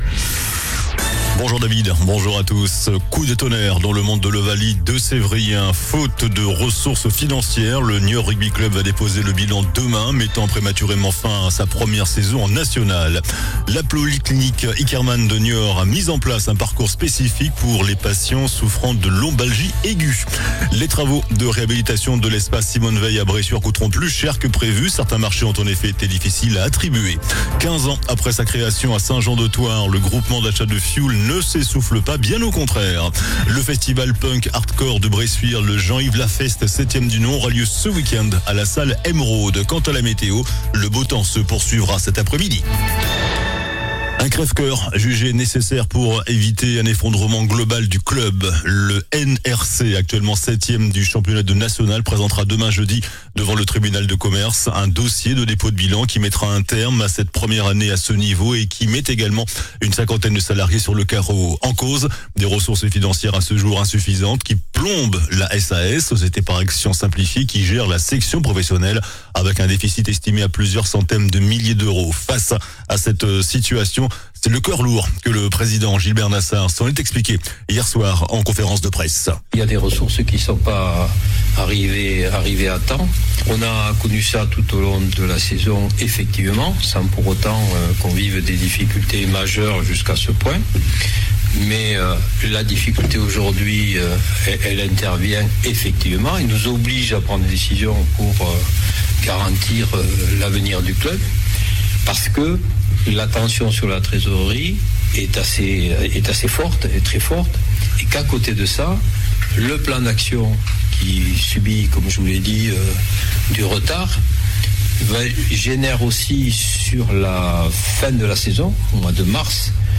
JOURNAL DU MERCREDI 04 MARS ( MIDI )